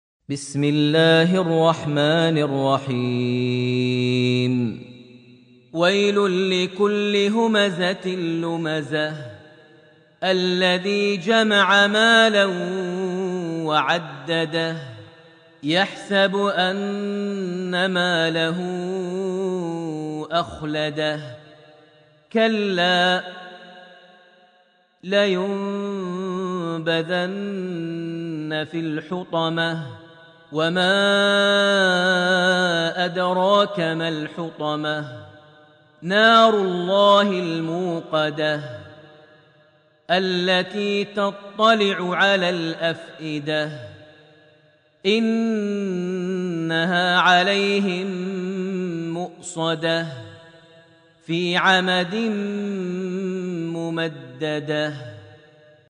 surat Al-homza > Almushaf > Mushaf - Maher Almuaiqly Recitations